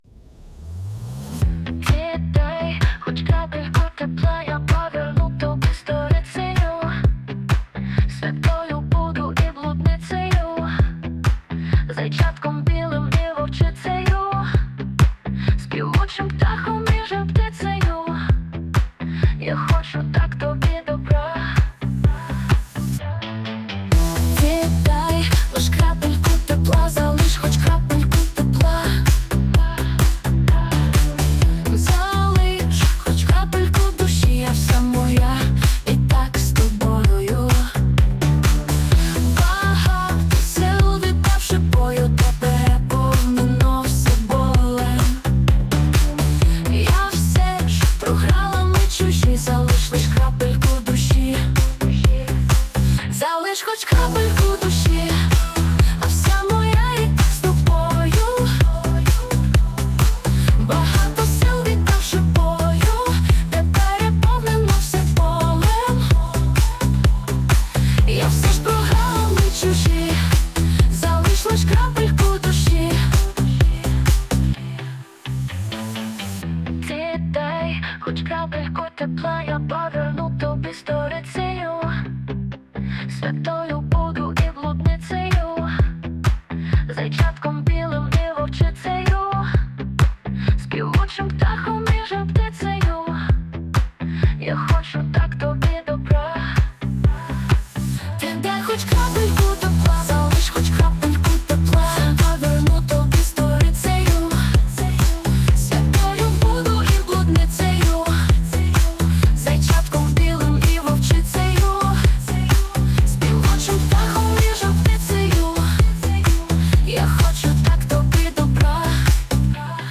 ТИП: Пісня
СТИЛЬОВІ ЖАНРИ: Ліричний